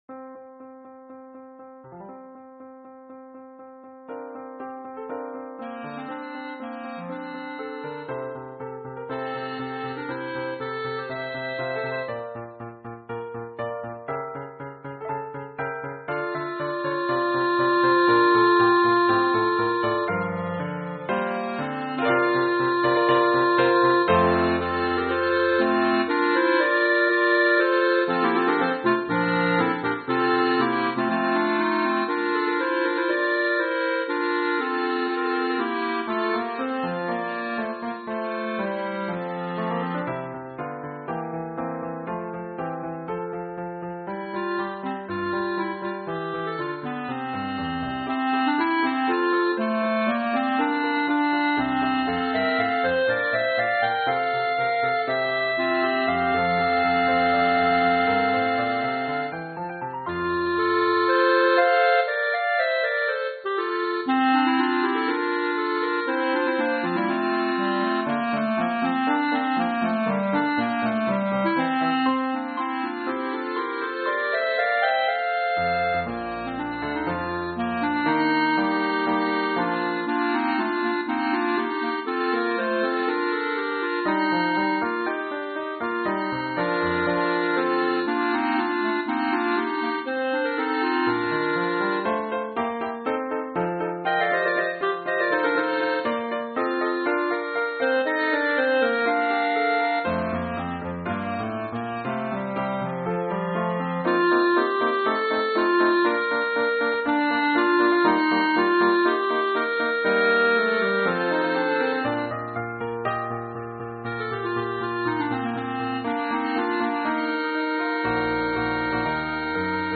Clarinet trio
Song Ternary
Clarinet trio with piano